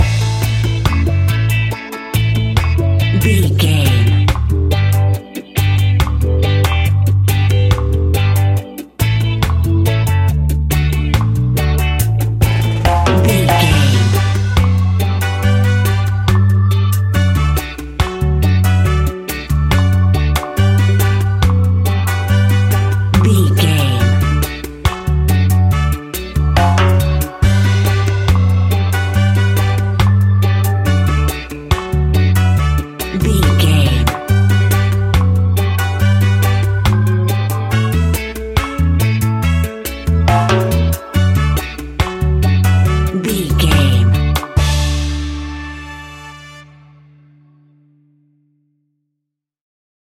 Classic reggae music with that skank bounce reggae feeling.
Ionian/Major
Slow
dub
laid back
chilled
off beat
drums
skank guitar
hammond organ
percussion
horns